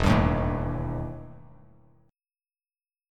EmM11 chord